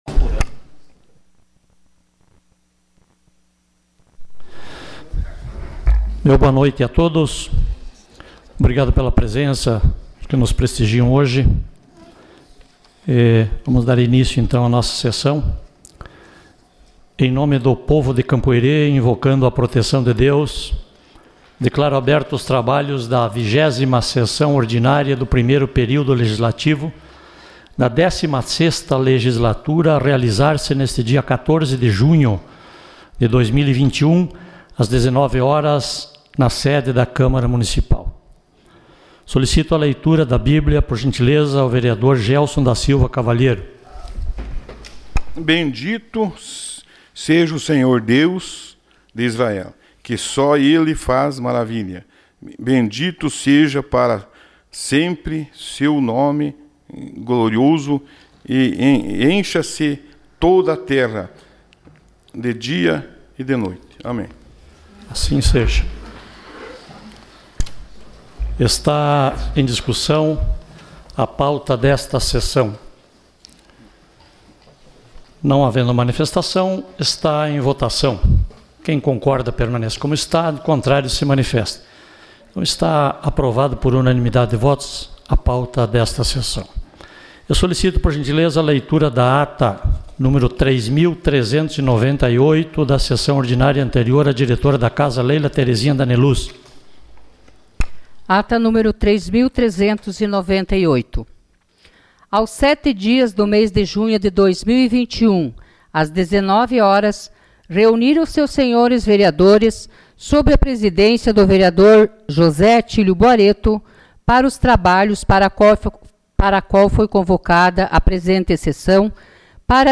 Sessão Ordinária dia 14 de junho de 2021